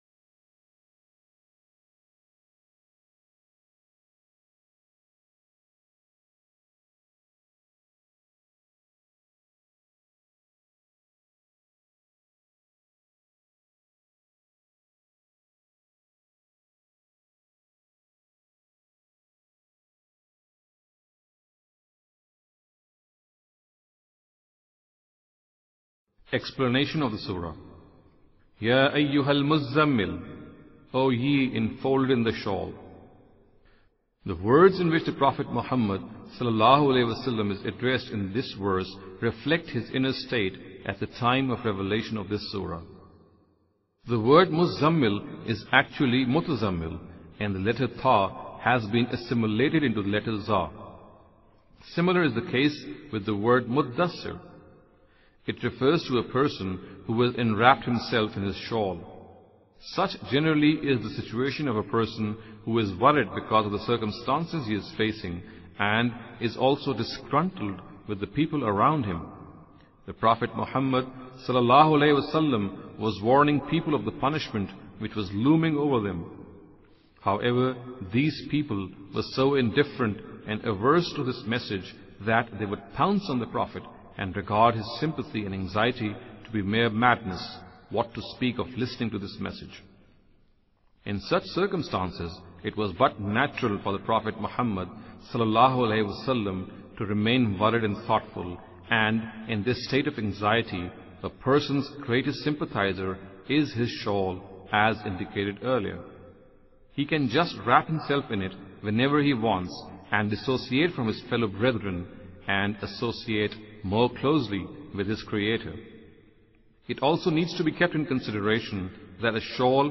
Imam Amin Ahsan Islahi's Dars-e-Qur'an.